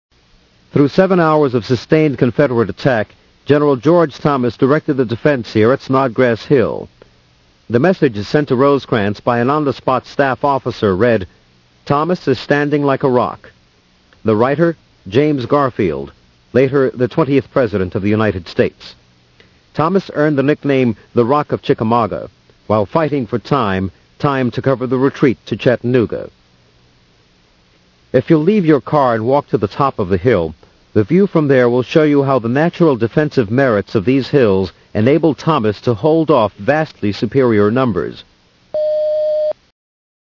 Small 157kb MP3 Sample of the audio tour.